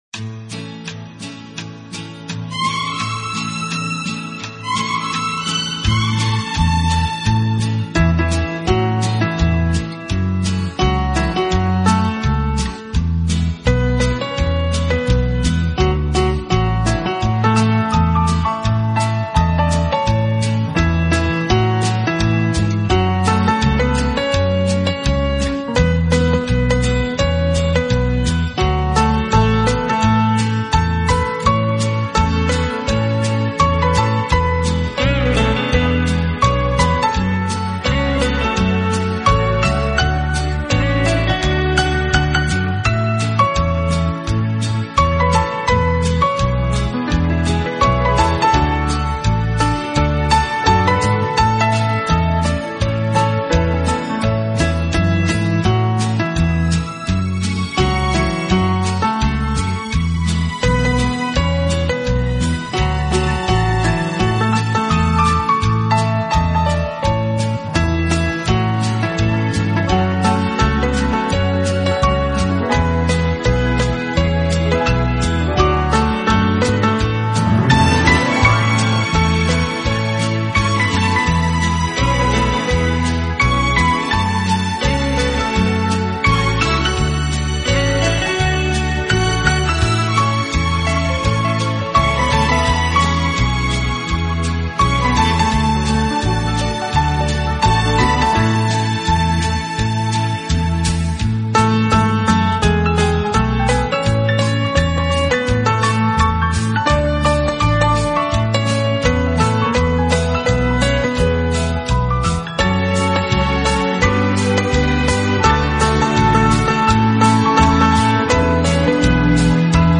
而缠绵悱恻。
本套CD全部钢琴演奏，